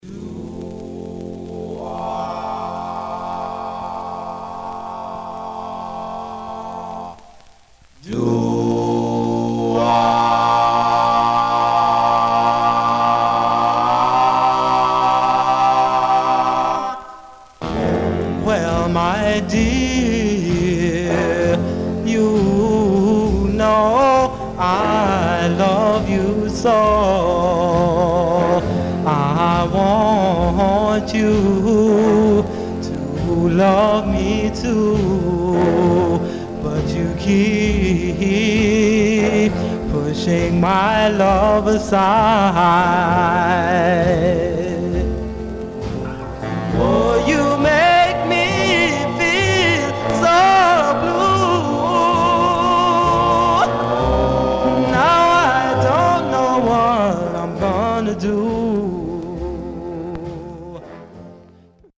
Male Black Group Condition